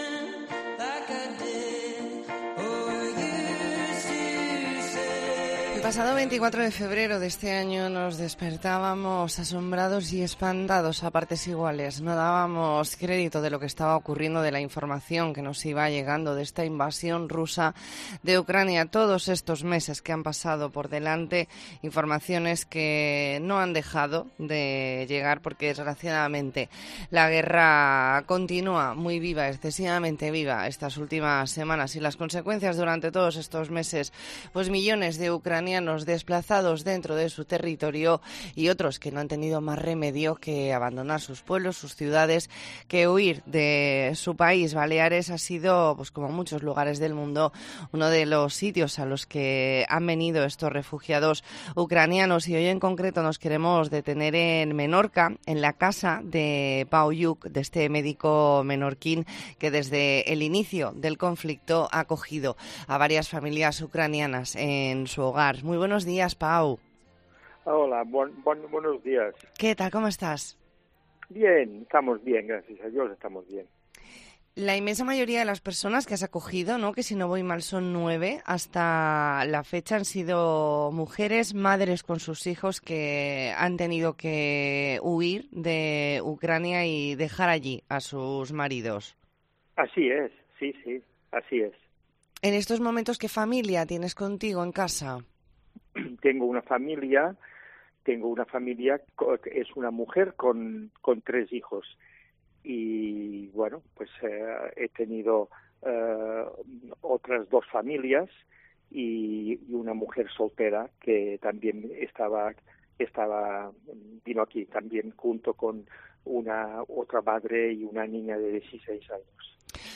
E ntrevista en La Mañana en COPE Más Mallorca, jueves 20 de octubre de 2022.